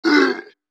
mixkit-enemy-death-voice-3168.wav